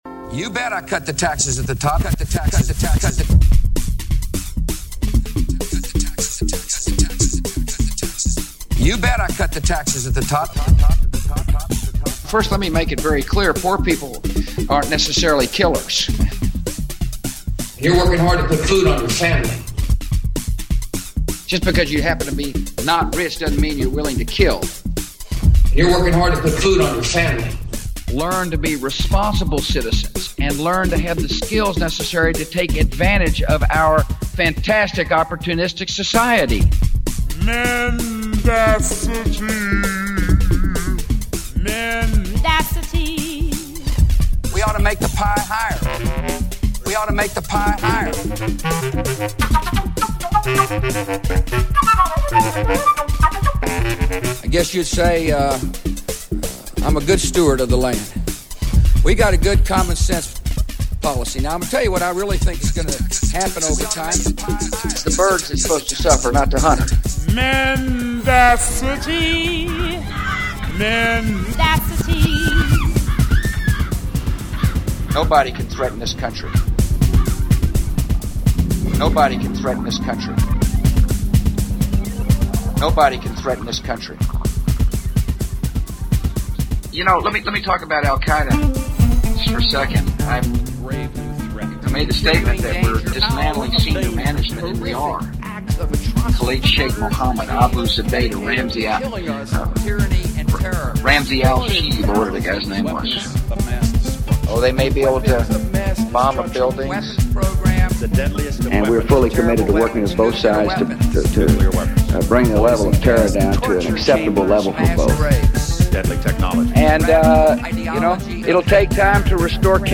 Improv jazz overlays GWB nuggets of wisdom.